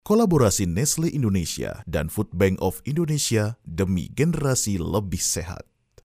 Comercial, Cool, Versátil, Maduro, Cálida
Telefonía